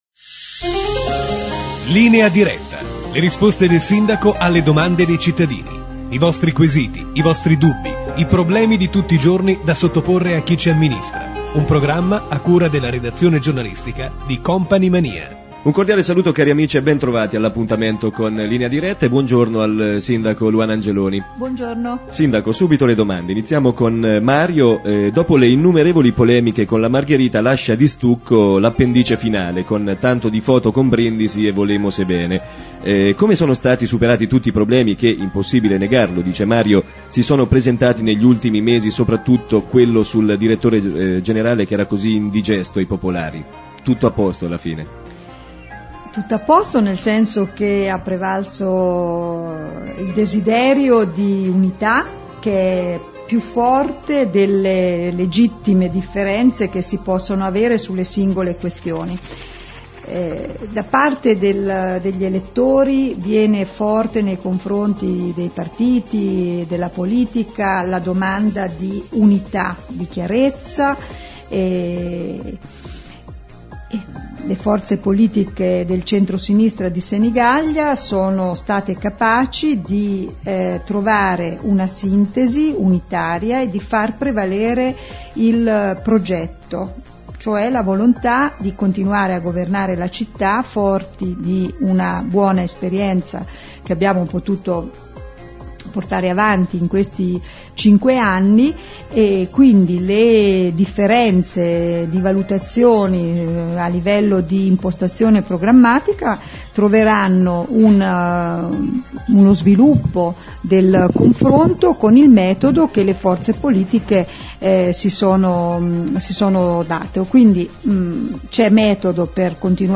Scarica e ascolta Linea Diretta del 10/02/2005 : il programma radiofonico in cui il Sindaco Angeloni risponde alle domande dei senigalliesi. Il sindaco risponde sul superamento dello “strappo” con la Margherita e sull’annosa questione del Direttore Generale al Comune come nuovo modello organizzativo; parla degli altri candidati a Sindaco e della frantumazione del centrodestra; della situazione dell’emergenza maltempo specialmente nelle frazioni ed infine del divieto di fumo assolutamente rispettato negli uffici comunali.